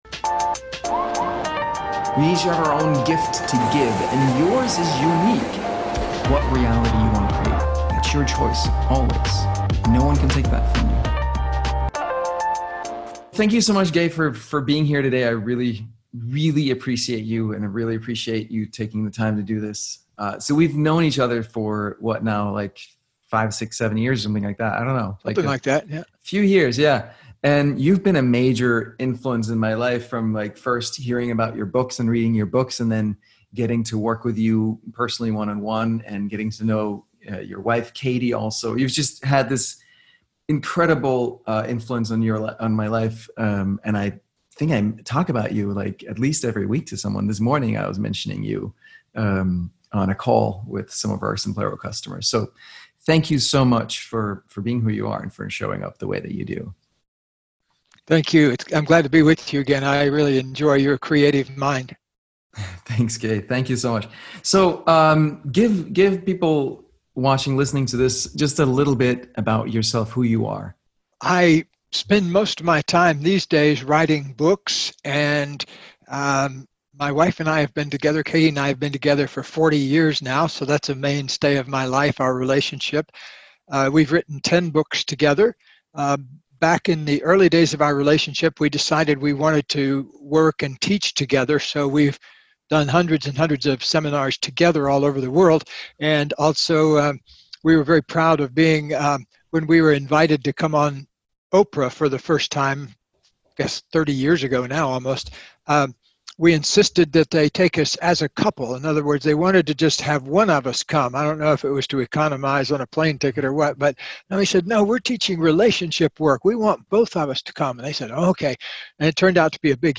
How To Reinvent Yourself Interview With Gay Hendricks